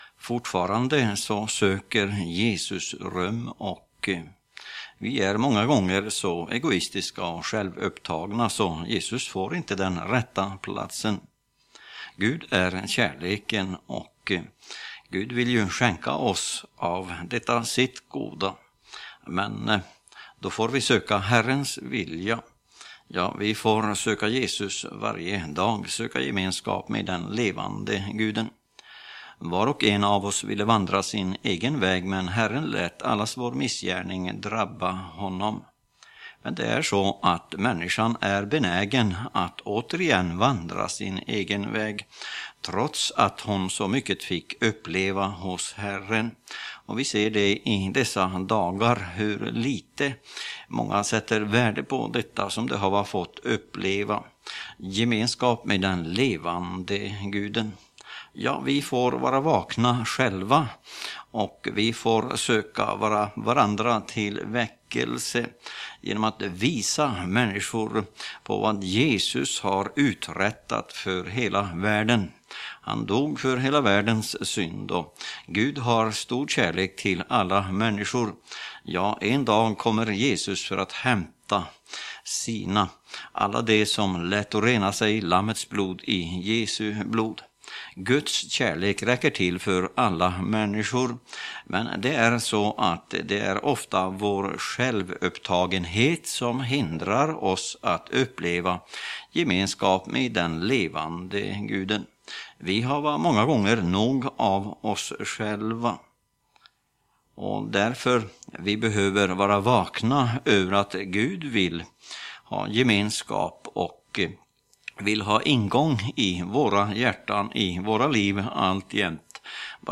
predikan_sjalvupptagenhet.mp3